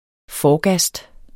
Udtale [ ˈfɒː- ]